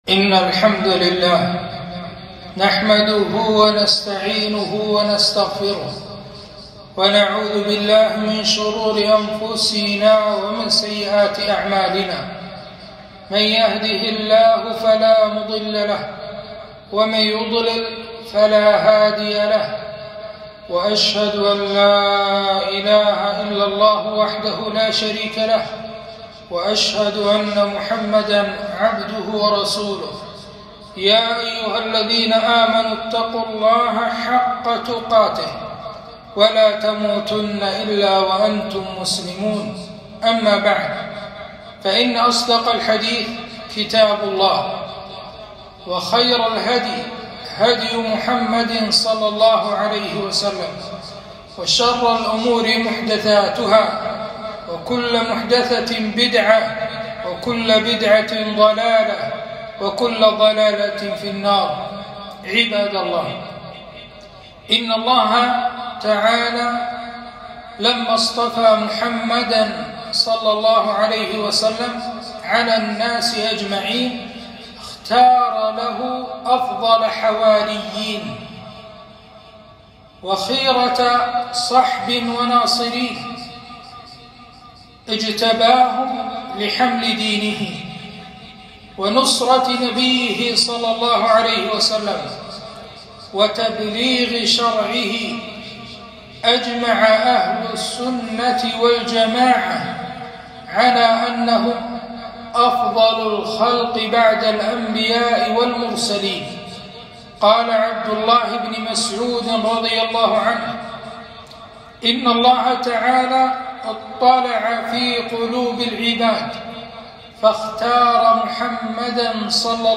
خطبة - فضل الصحابة وحقوقهم